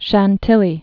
(shăn-tĭlē, shäɴ-tē-yē)